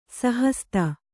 ♪ sahasta